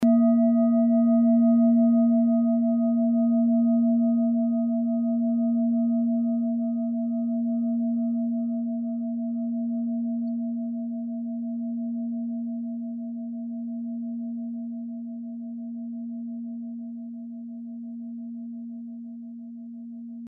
Orissa Klangschale Nr.22
Sie ist neu und wurde gezielt nach altem 7-Metalle-Rezept in Handarbeit gezogen und gehämmert.
Hörprobe der Klangschale
Die 37. Oktave dieser Frequenz liegt bei 234,16 Hz und findet sich auf unserer Tonleiter nahe beim "B".
klangschale-orissa-22.mp3